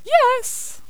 princess_ack2.wav